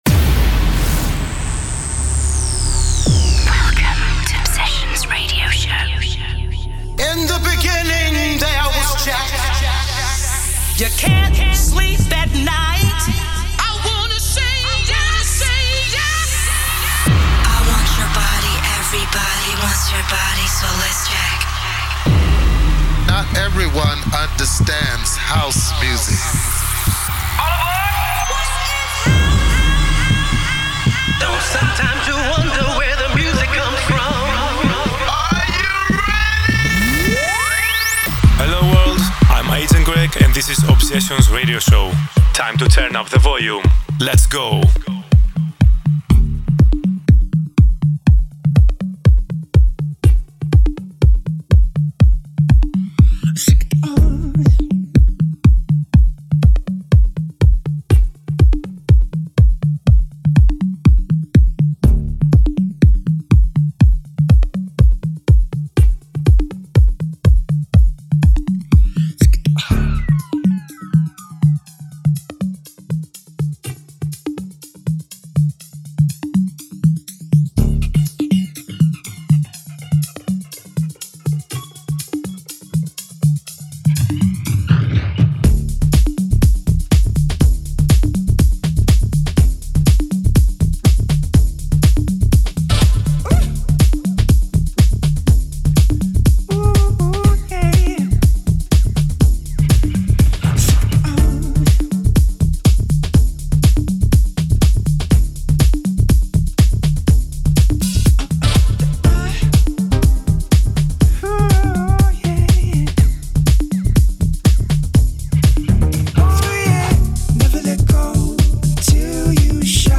weekly 1 hour music mix
Expect nothing but pure House music.